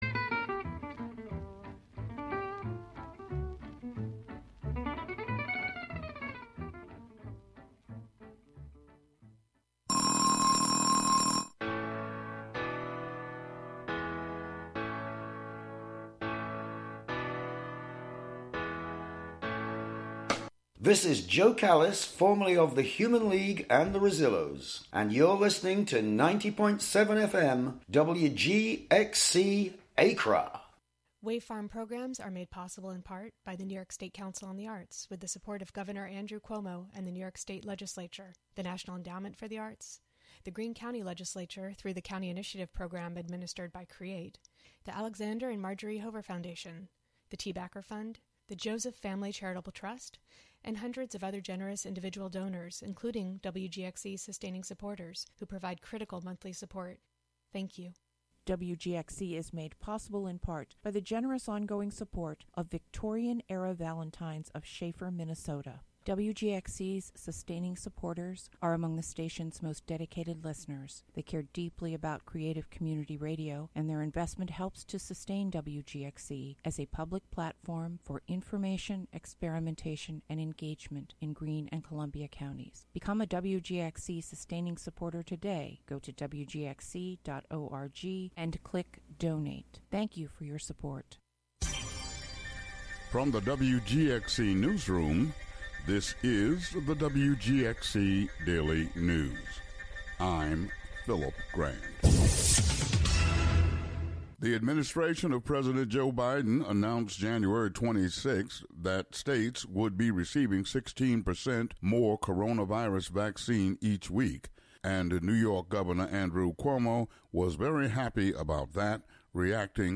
After the noon local news, The Bedazzled Radio Hour features pop, rock, and soul obscurities from the '60s, '70s, '80s and beyond, including freakbeat, baroque rock, glam, bubblegum, pop-psych, sunshine pop, garage rock, girl groups, British invasion, punk, funk, new wave, Northern soul, and more.